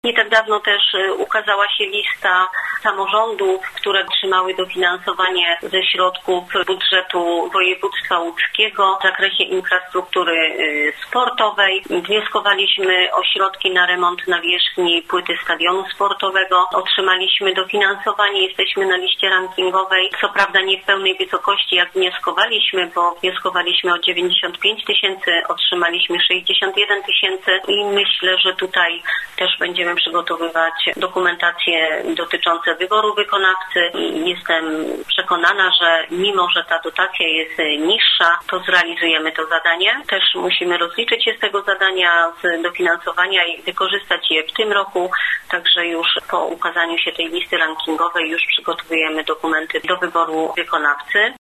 – informowała Renata Kostrzycka, zastępca wójta gminy Osjaków.